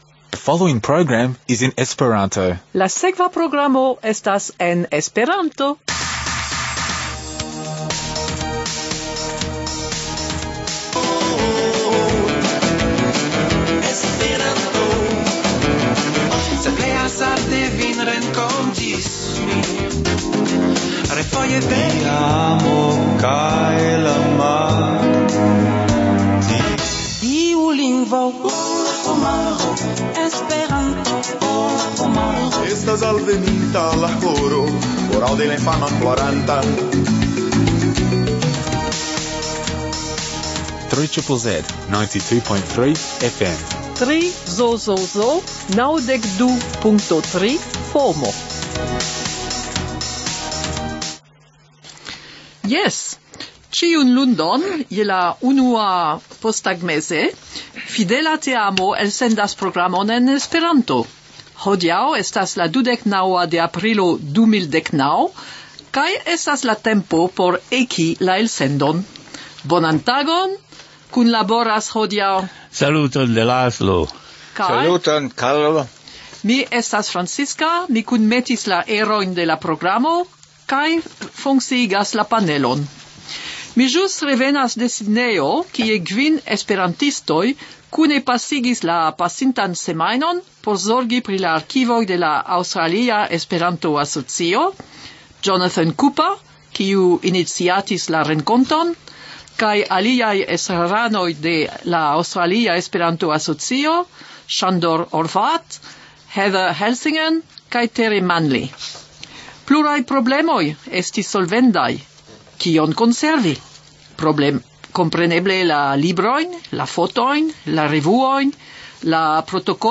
Intervjuo
Legado